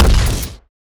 weapon_shotgun_002.wav